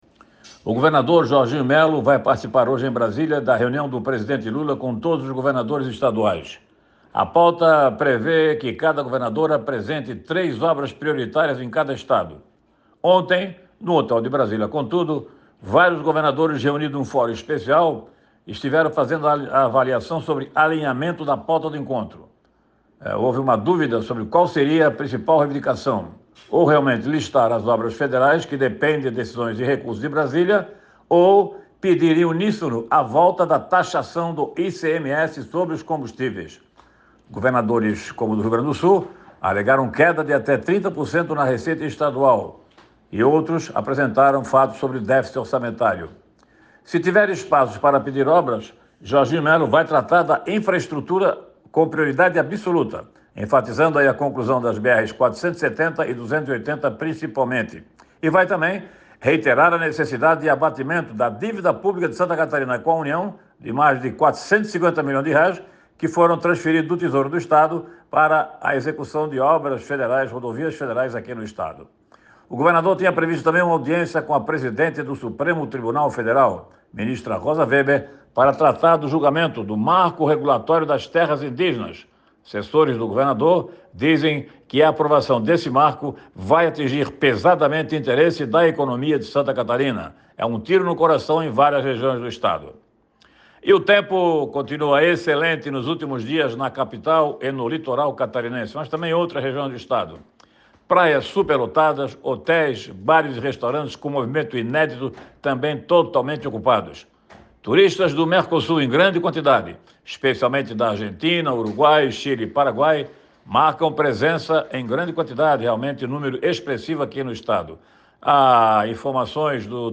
Confira na íntegra o comentário